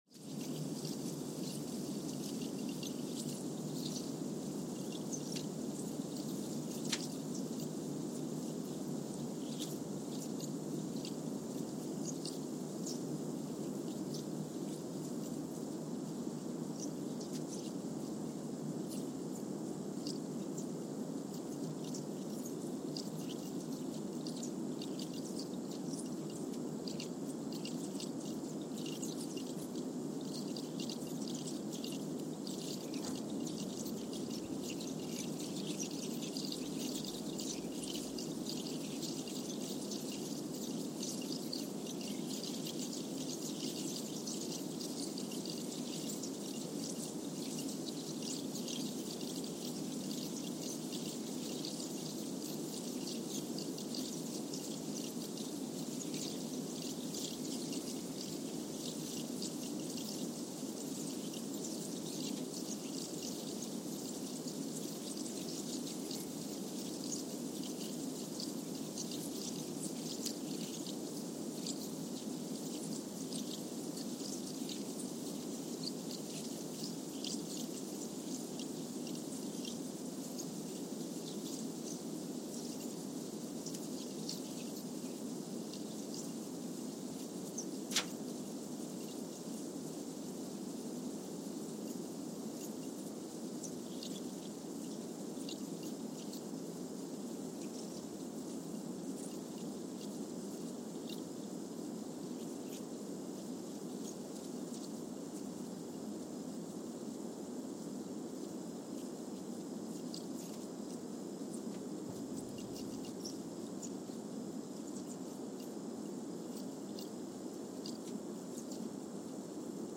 San Juan, Puerto Rico (seismic) archived on February 14, 2023
Station : SJG (network: IRIS/USGS) at San Juan, Puerto Rico
Sensor : Trillium 360
Speedup : ×1,000 (transposed up about 10 octaves)
Loop duration (audio) : 05:45 (stereo)
SoX post-processing : highpass -2 90 highpass -2 90